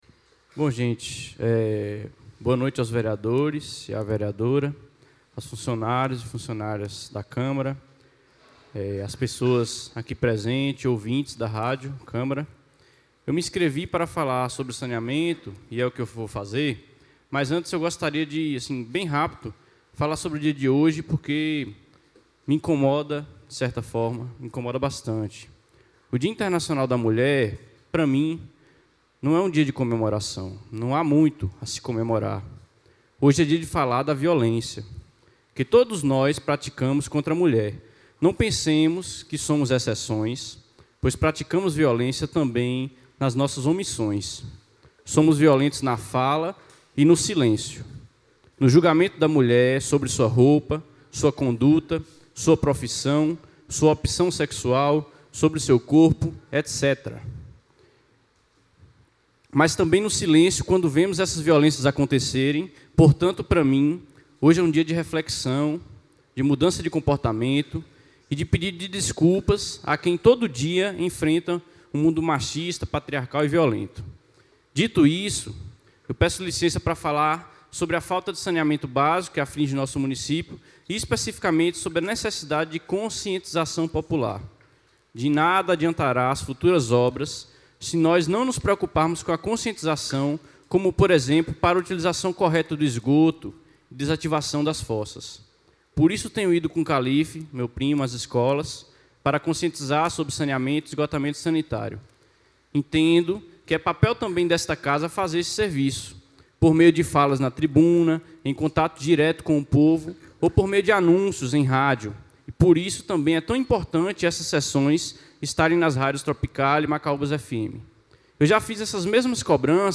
A 13ª Sessão Ordinária do Primeiro Período Legislativo da Legislatura 2017-2020 da Câmara Municipal de Macaúbas foi realizada às 19h00min, no Plenário da Casa da Cidadania.